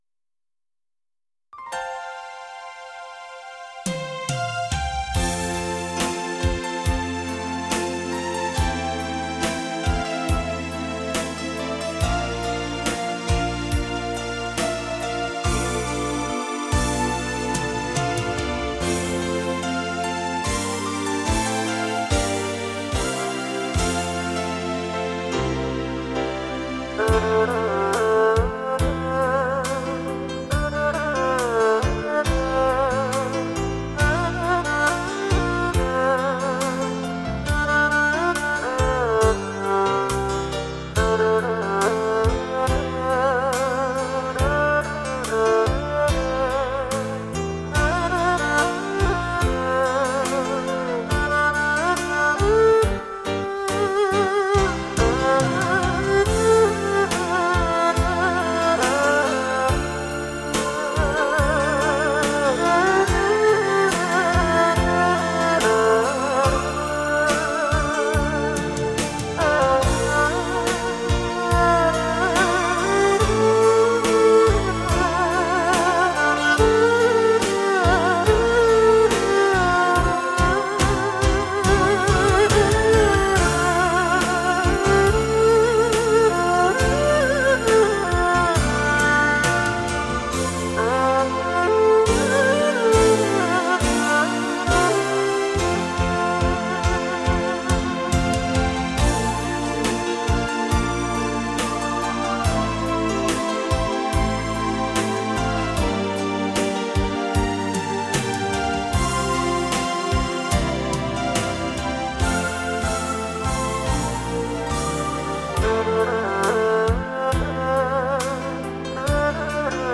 二胡恋曲
二胡演奏